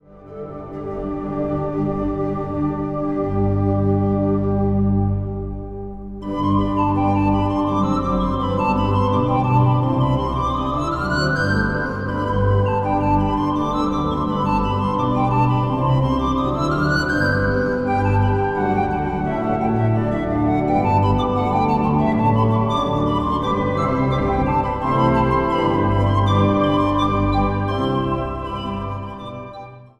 Kerstmuziek vanuit Bolsward
orgel
panfluit.
Instrumentaal | Panfluit